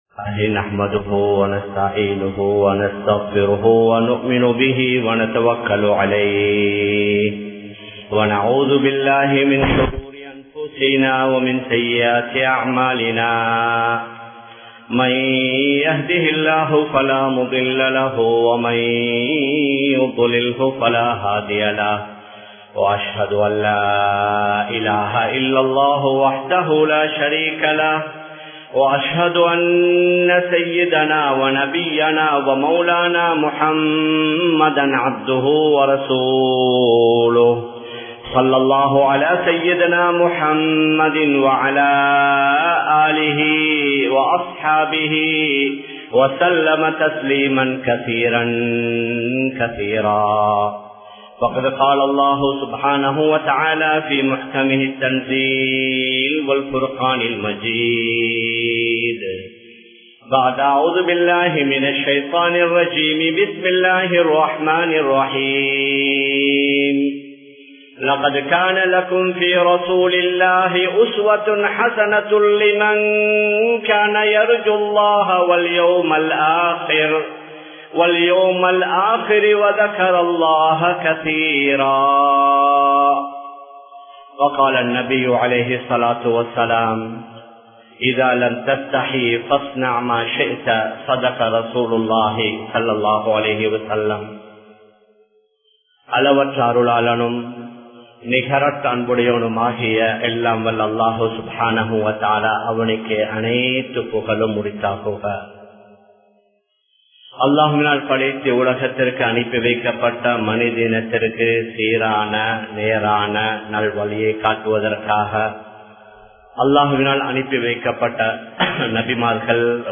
இஸ்லாம் கூறும் நாகரீகம் | Audio Bayans | All Ceylon Muslim Youth Community | Addalaichenai
Kollupitty Jumua Masjith